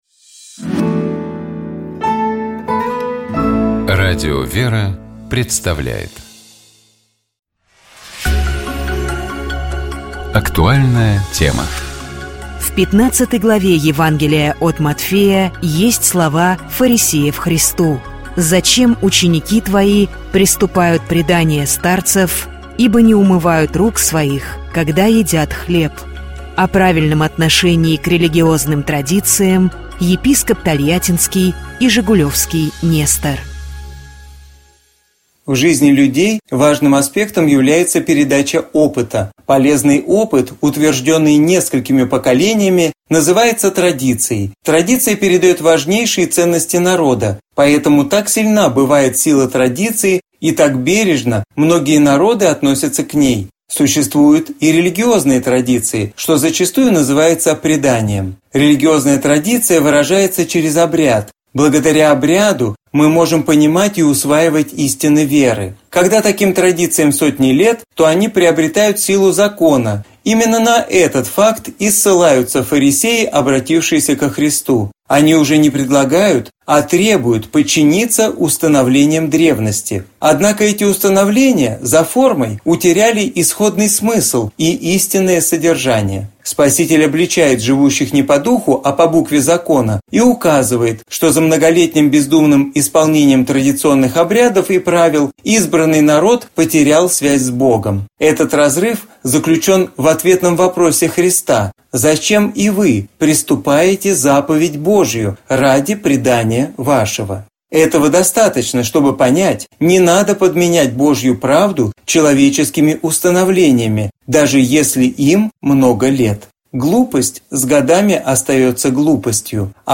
О правильном отношении к религиозным традициям, — епископ Тольяттинский и Жигулёвский Нестор.